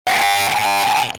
Rage Glitch 2 - Bouton d'effet sonore